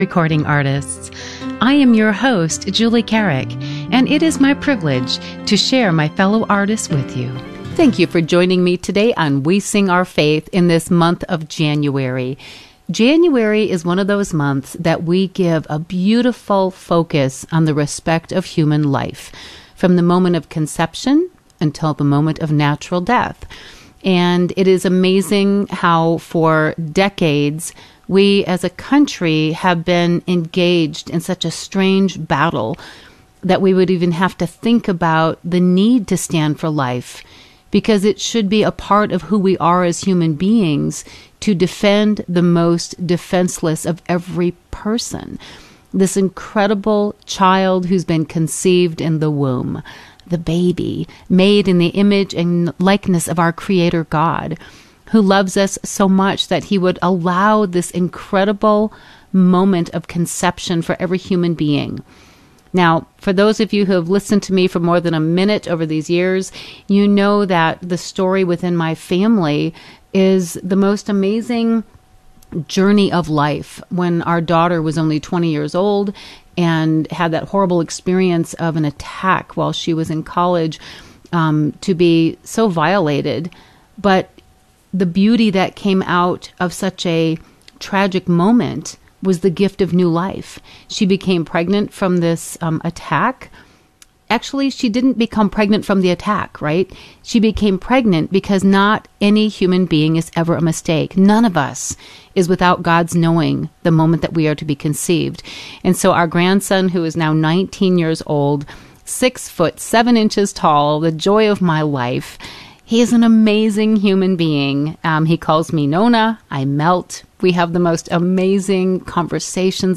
A new uplifting Catholic music show